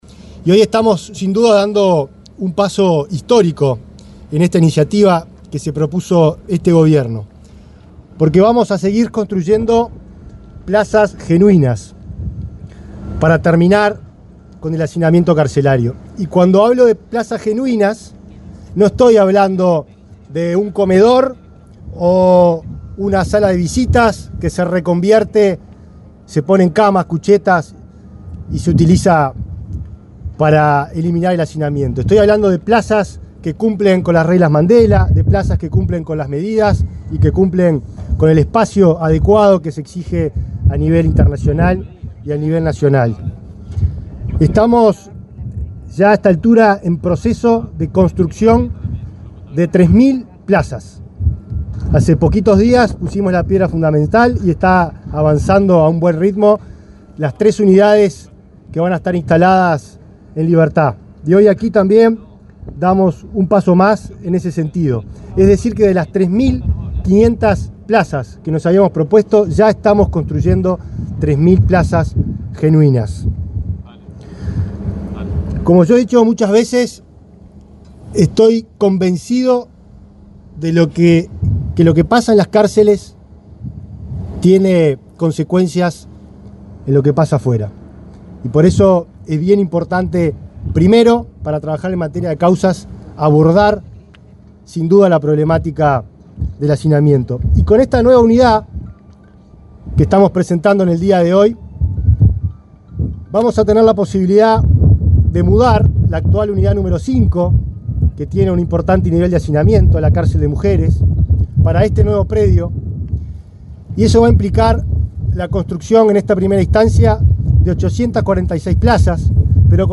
Palabras del ministro del Interior, Nicolás Martinelli
En el marco de la ceremonia de colocación de la piedra fundamental de una cárcel para mujeres en Punta de Rieles, se expresó el ministro del Interior,